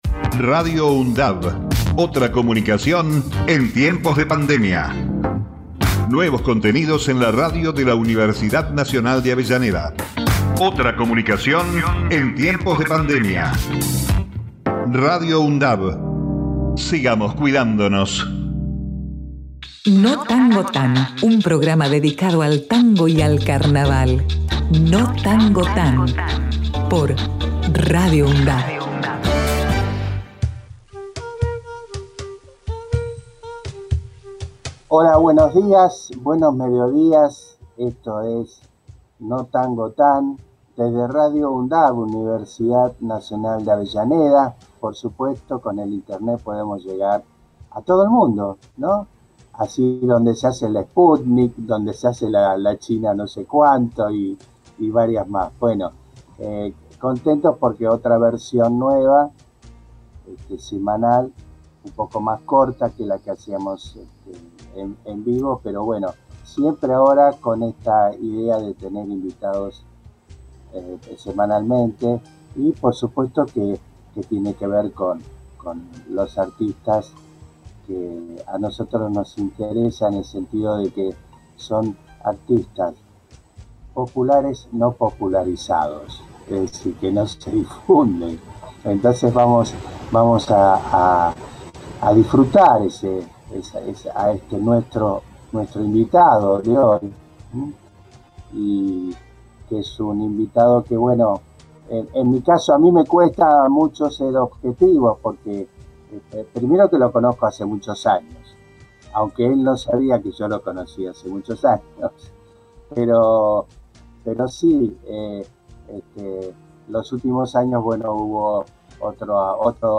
Notangotan es un programa temático que aborda la cultura, fundamentalmente musical de Buenos Aires y la región que nos abarca, la Cuenca del Río de la Plata, hacedora del género que nos convoca, el tango y el carnaval.